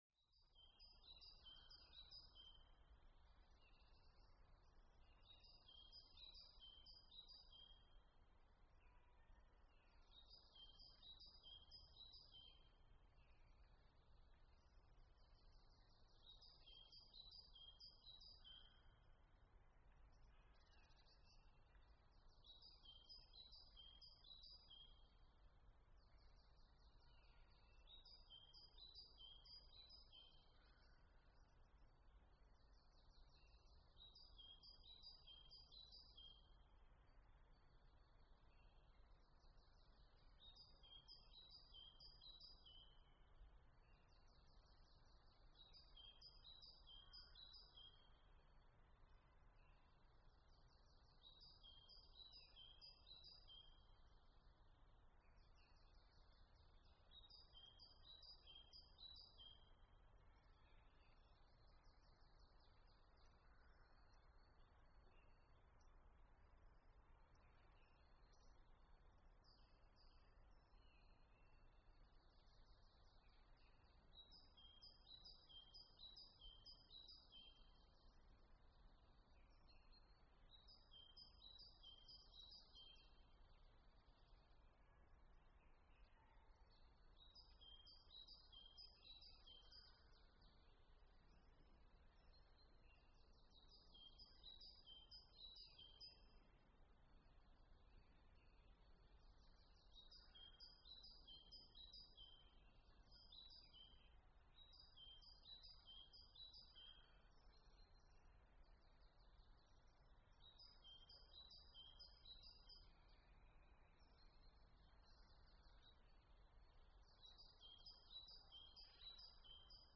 hs2-outside-quiet.mp3